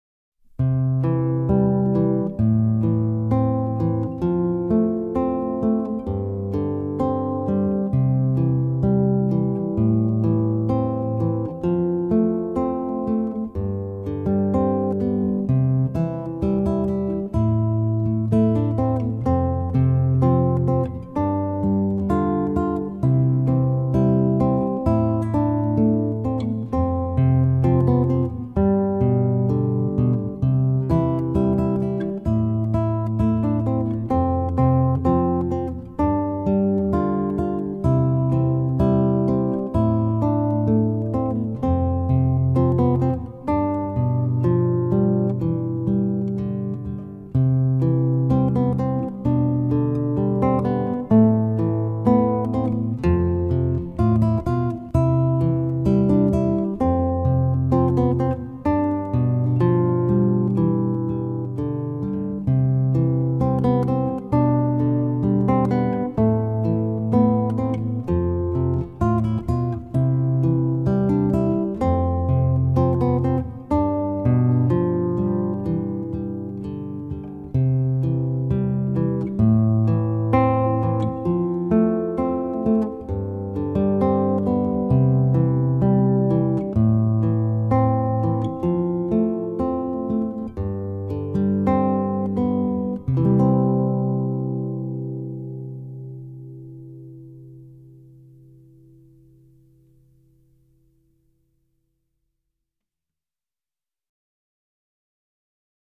בעמוד זה תמצאו כ-80 עיבודי גיטרה ברמת ביניים